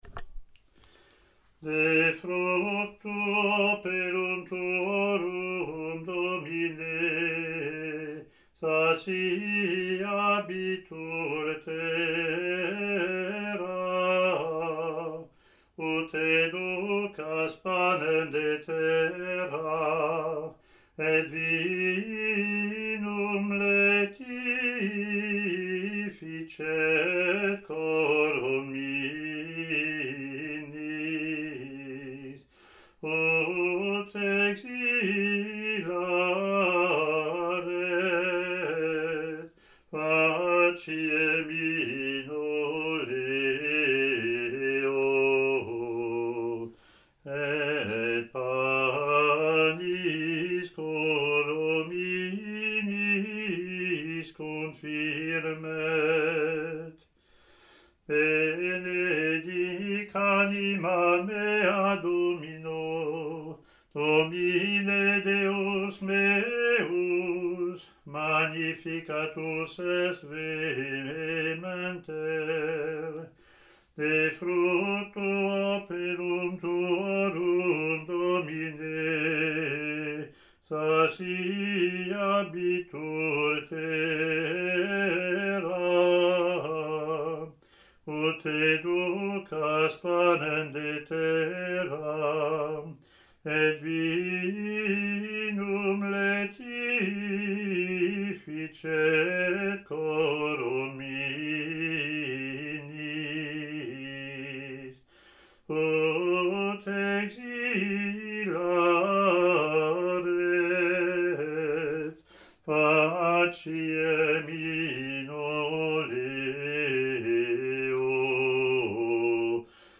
Latin antiphon + verse)